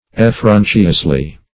Effrontuously \Ef*fron"tu*ous*ly\